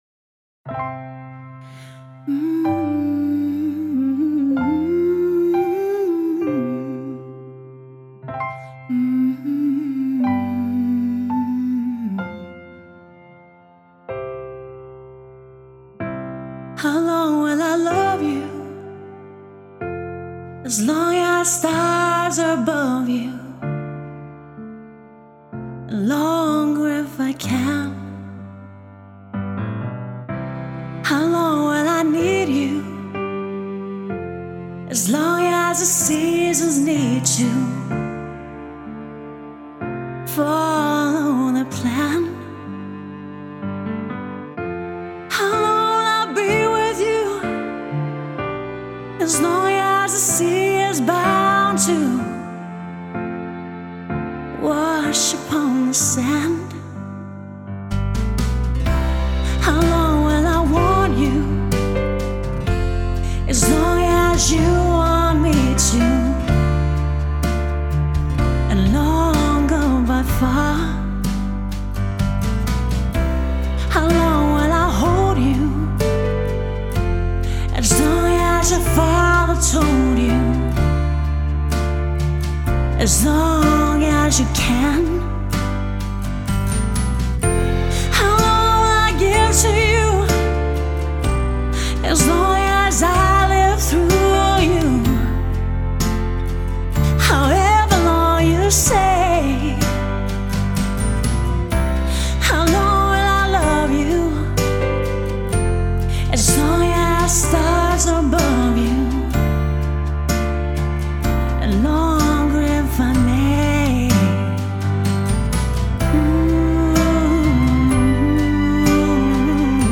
pop rock wedding and cabaret singer
a highly engaging personality and a rich and powerful voice.